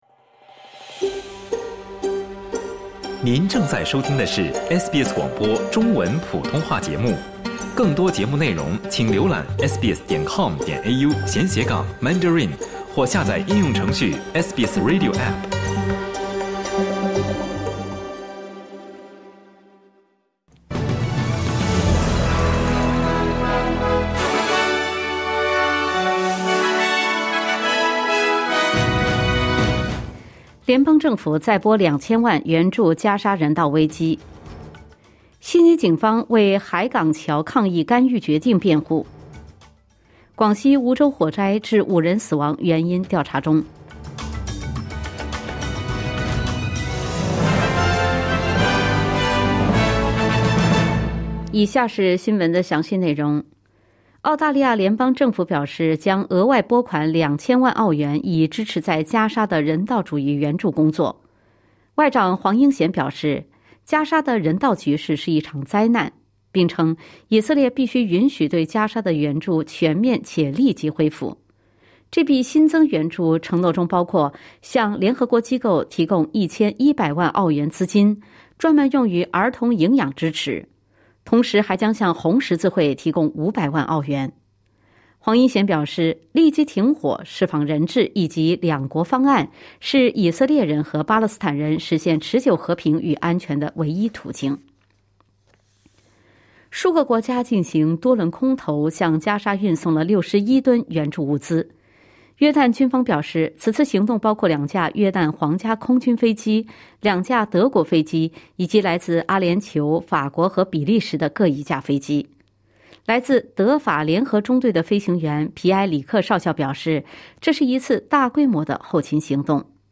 SBS早新闻（2025年8月4日）